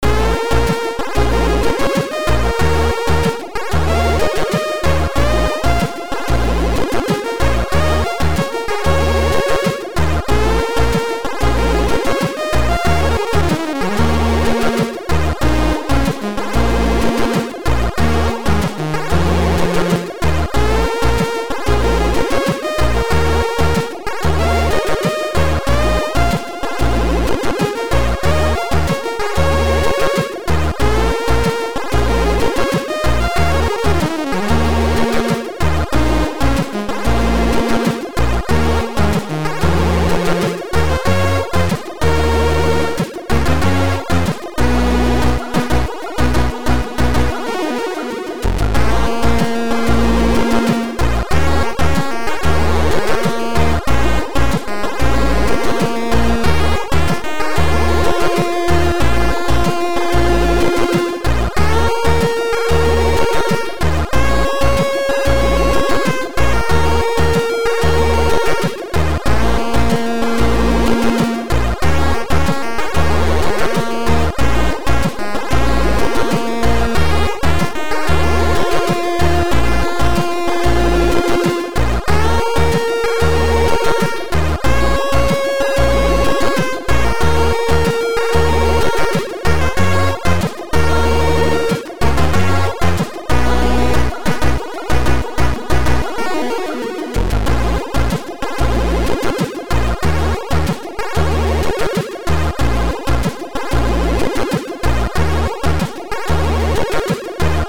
Title Divided Type AHX v2 Tracker